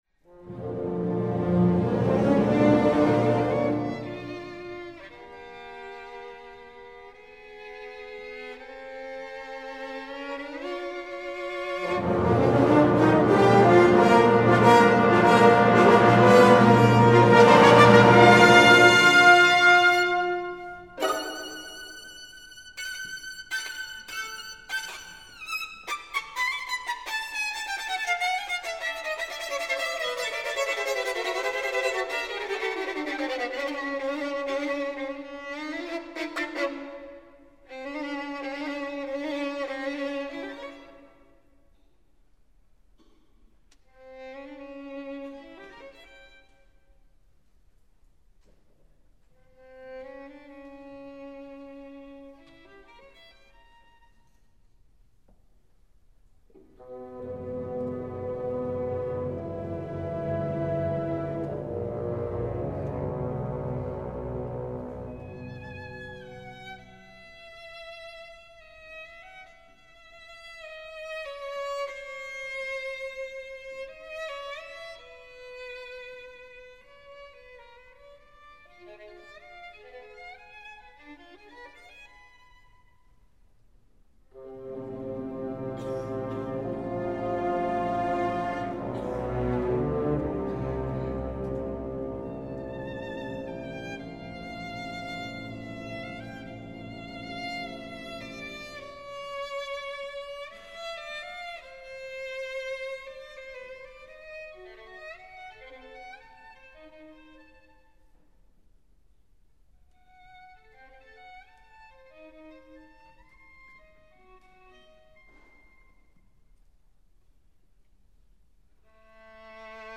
violin solo
recorded live on January 15, 2009 at the Konzerthaus am Gendarmenmarkt Berlin